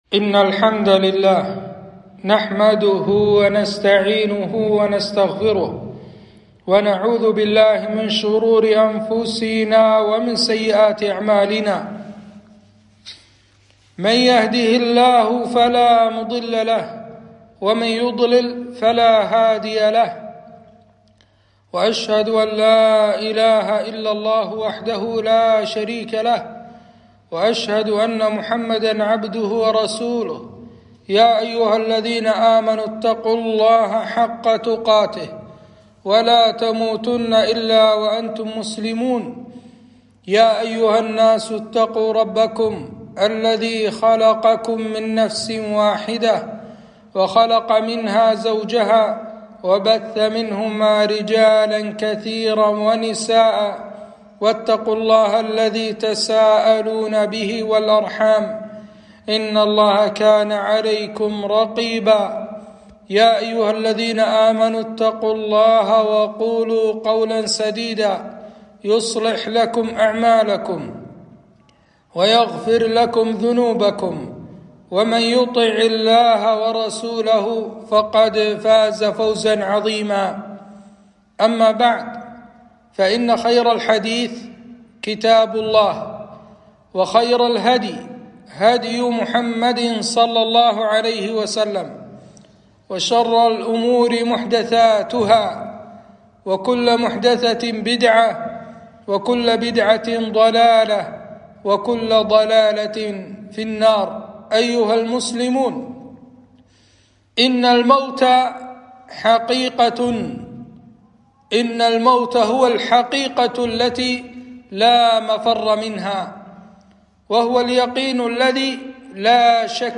خطبة - على فراش الموت